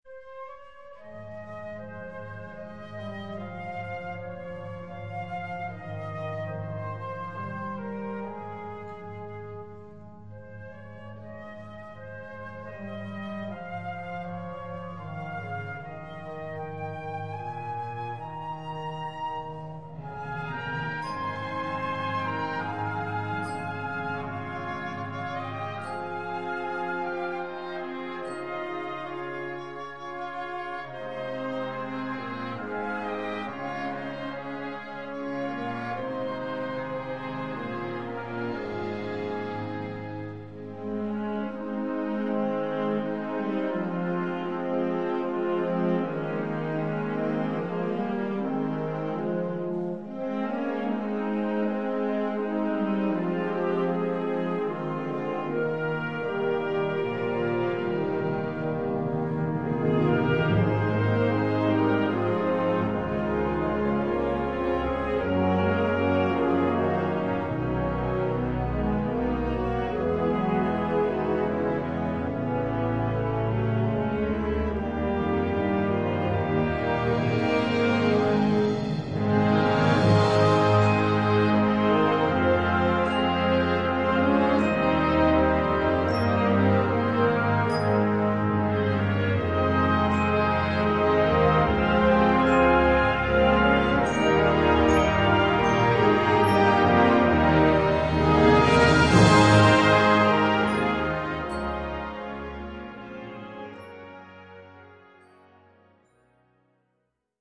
Gattung: Konzertante Ballade
Besetzung: Blasorchester